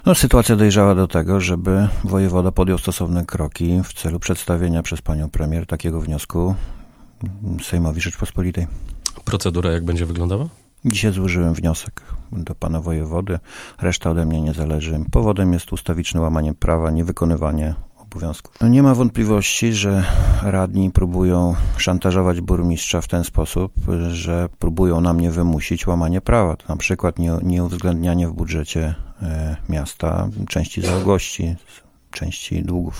Z wnioskiem o podjęcie procedury odwołania Rady Miasta Sejny wystąpił w piątek (03.02) do wojewody podlaskiego Arkadiusz Nowalski, burmistrz miasta. Informację podał w audycji „Gość Radia 5”. Jak wyjaśnił, w jego ocenie Rada Miasta z premedytacją łamie prawo i pogarsza sytuację miasta.
Arkadiusz-Nowalski-burmistrz-Sejn-gość-Radia-5-o-procedurę-odwołania-Rady-Miasta-1.mp3